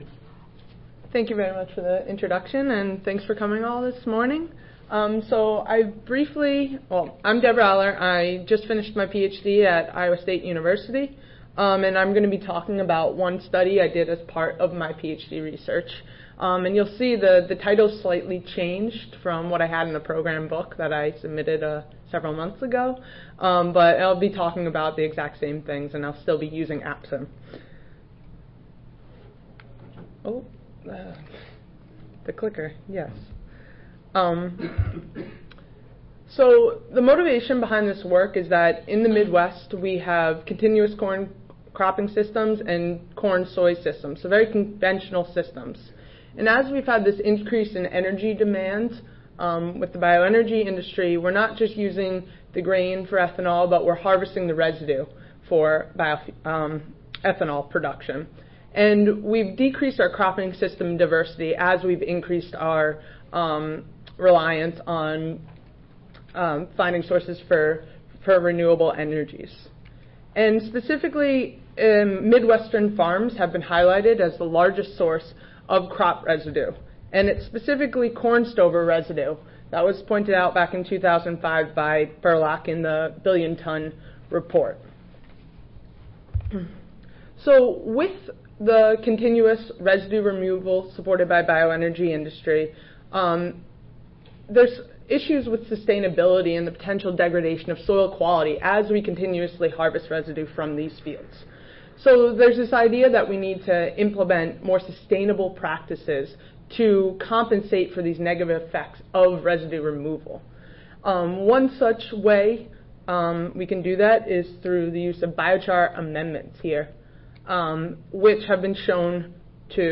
See more from this Division: ASA Section: Climatology and Modeling See more from this Session: Examples of Model Applications in Field Research Oral